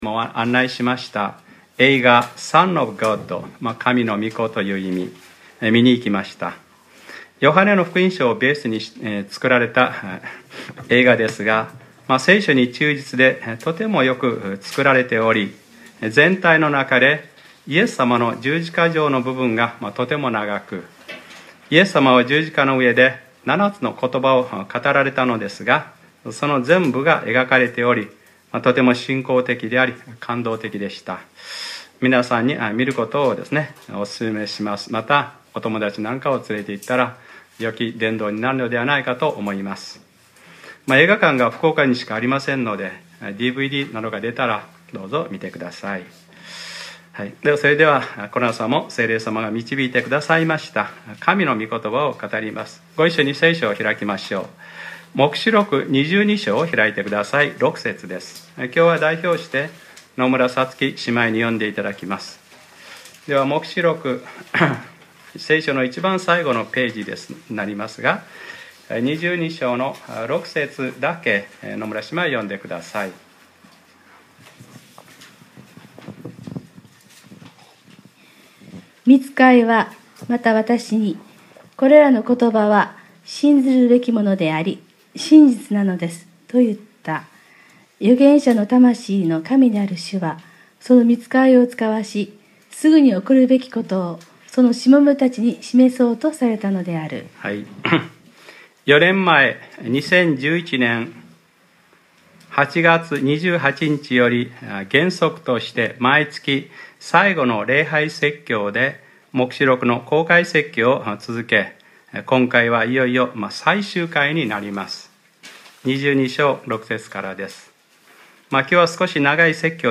2015年01月25日（日）礼拝説教 『黙示録ｰ３７：見よ。わたしはすぐに来る』